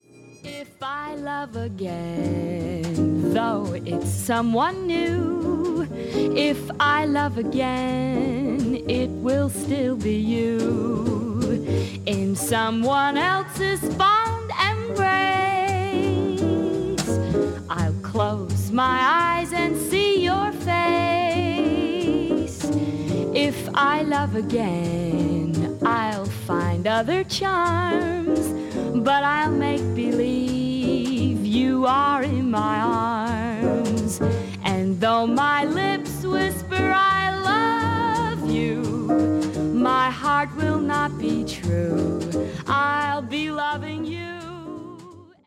この時代の歌手ならではの明朗快活っぷりに、深く息をするようなデリケートな歌い方もできるアメリカのシンガー
恋をテーマにしたスロー〜ミディアム・テンポの曲でほぼ占められる本作。